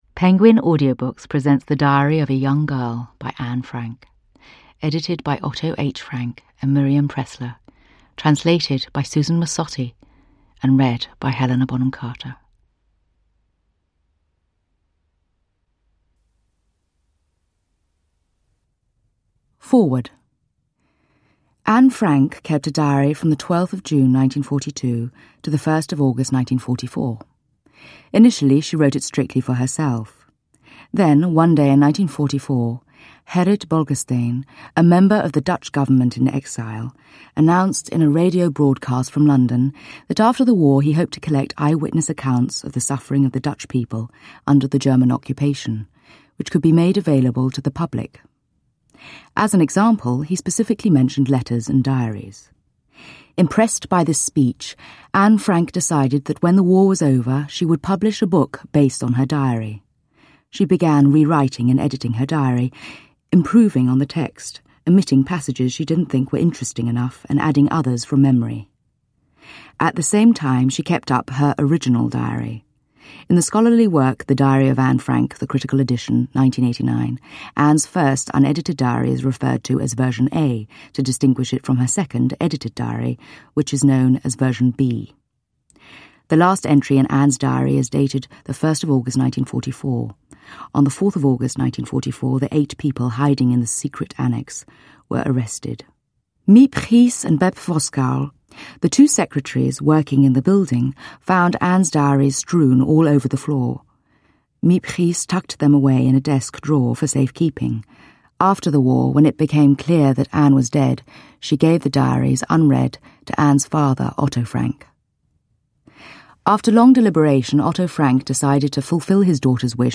The Diary of a Young Girl – Ljudbok
Insightfully read by the actress Helena Bonham Carter.
Uppläsare: Helena Bonham Carter